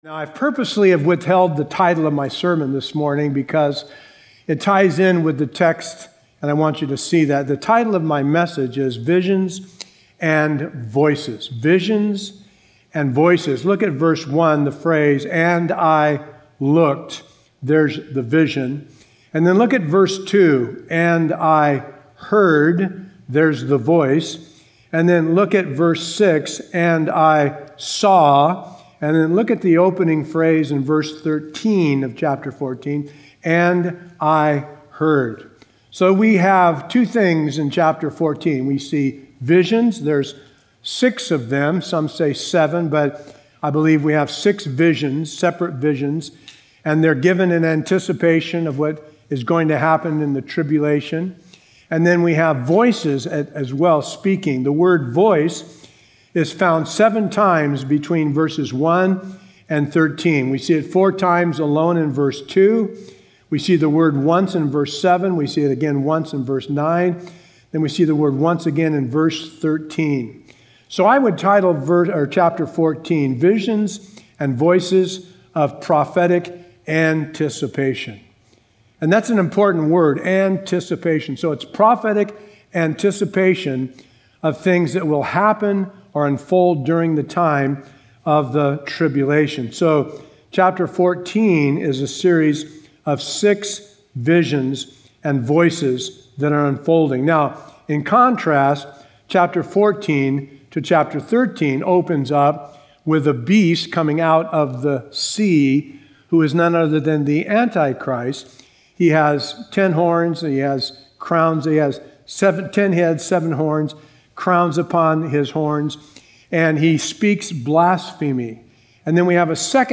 A verse-by-verse expository sermon through Revelation 14:1-13